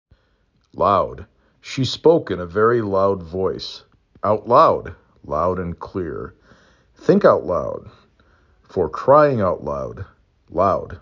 l ow d